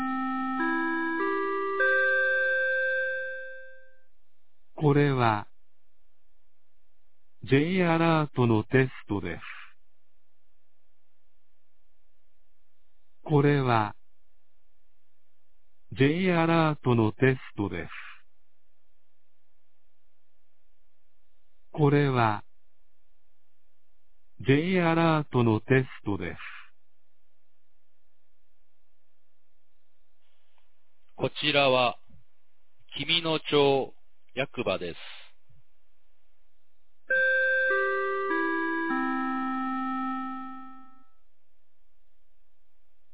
2021年05月19日 11時01分に、紀美野町より全地区へ放送がありました。